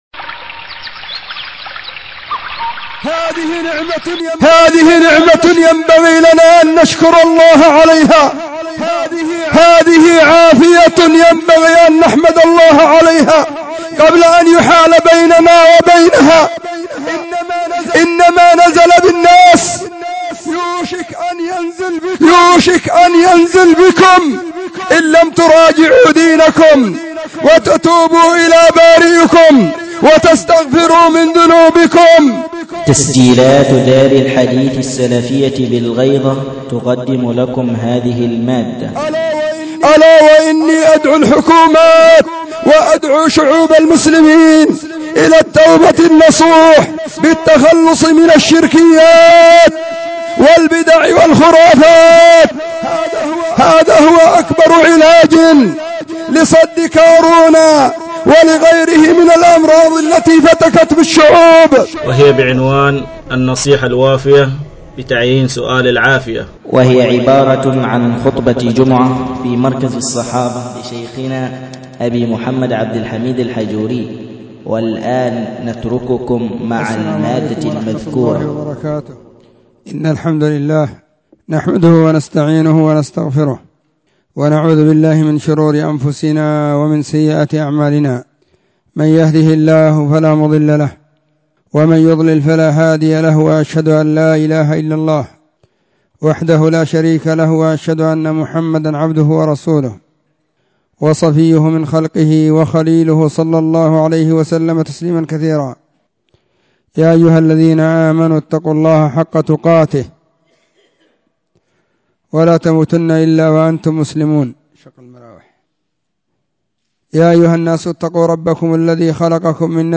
📢 وكانت في مسجد الصحابة بالغيضة، محافظة المهرة – اليمن.